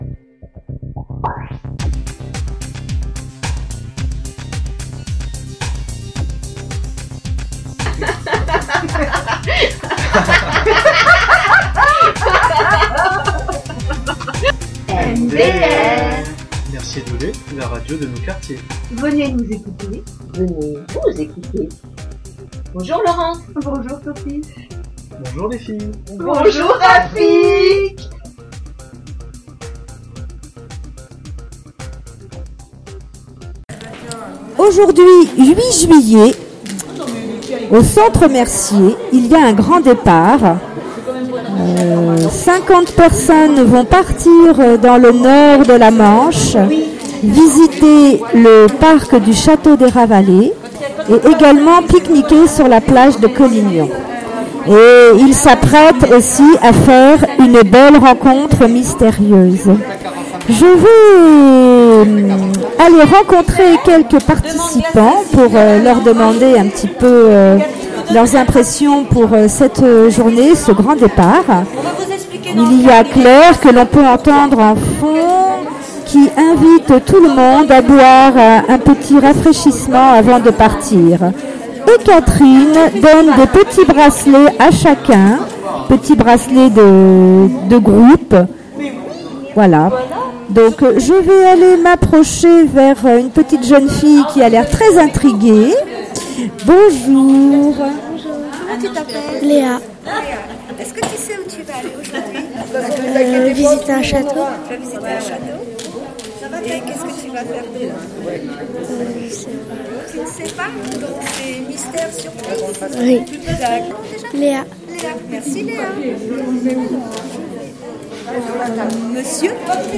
Ballade du 8 juillet au château des Ravalet
Barbecue